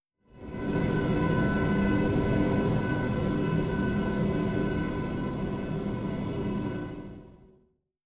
Playstation 8 Startup.wav